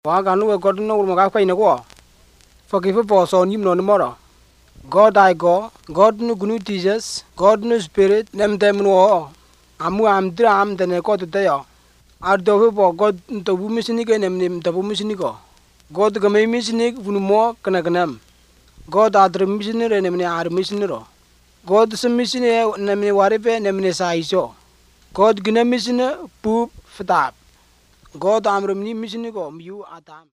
Most use a storytelling approach. These are recorded by mother-tongue speakers
Bible Stories, Discipleship, Music